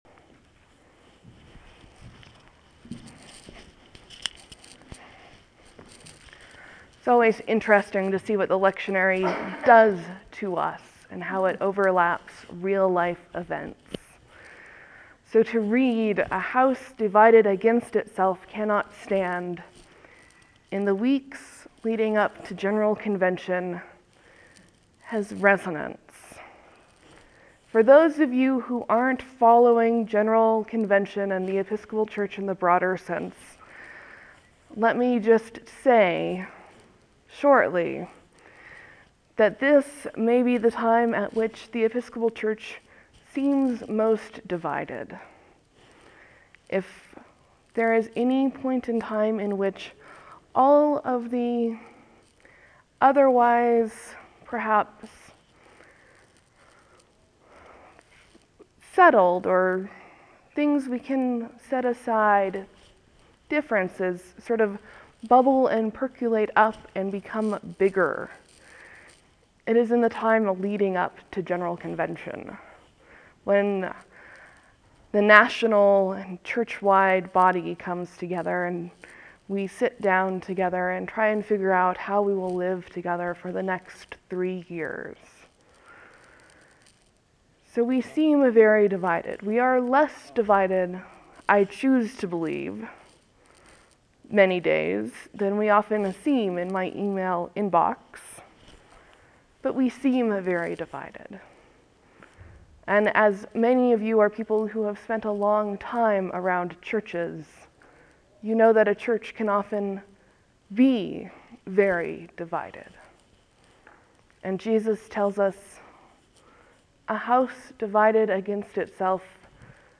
There will be a few moments of silence before the sermon starts.